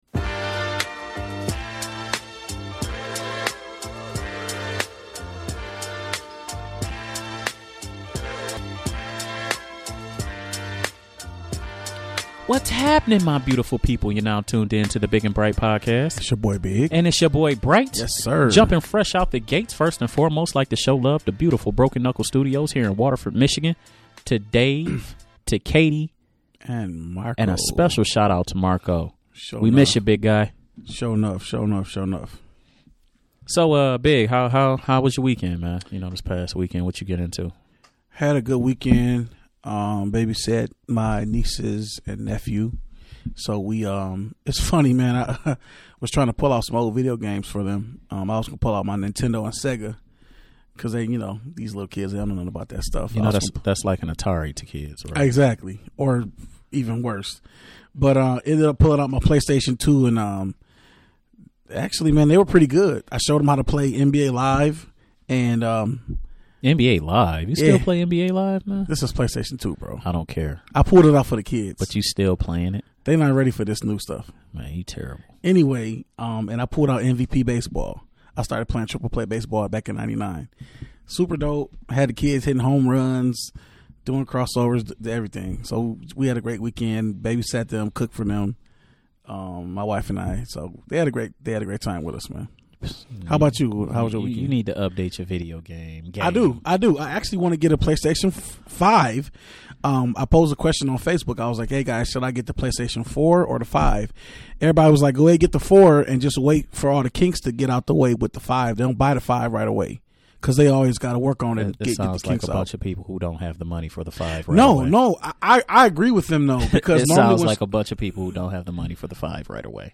The point/counterpoint sports talk powerhouses return this week to get you up to speed on what's going on in sports around the country and even around the globe.